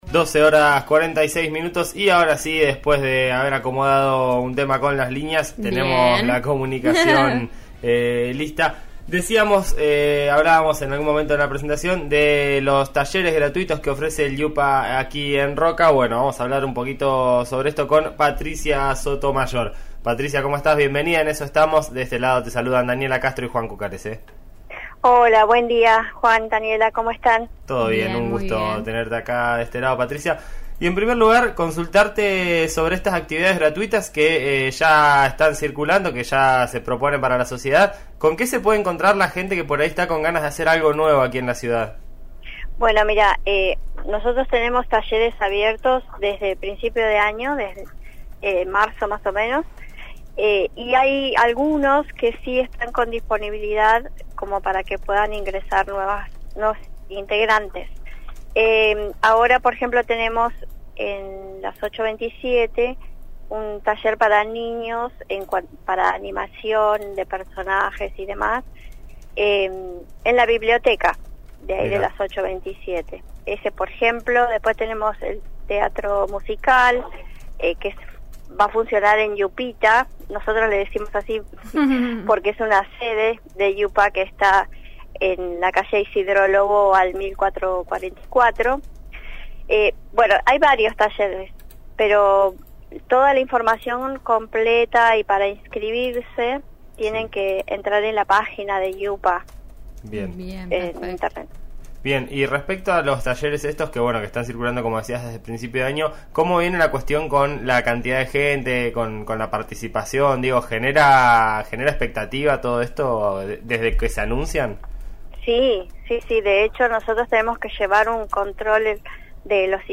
al aire de En Eso Estamos.